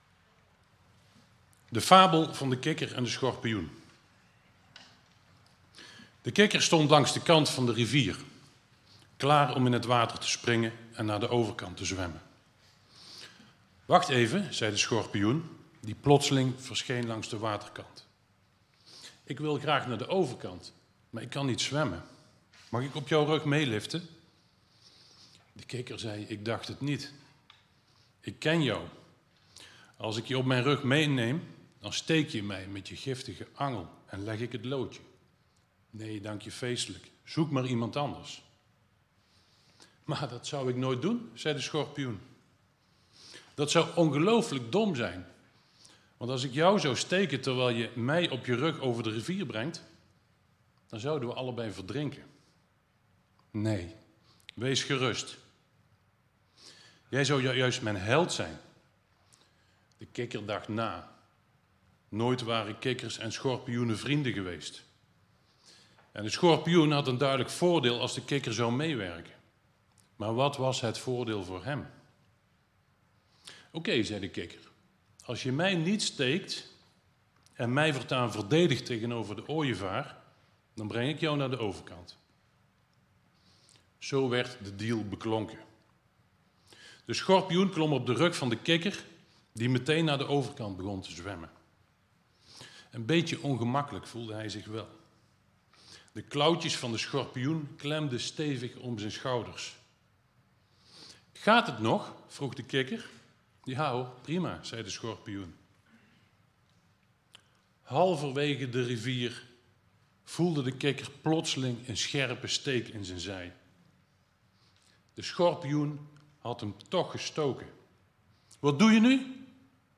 Toespraak 29 augustus: De meeste mensen deugen....of toch niet (helemaal)?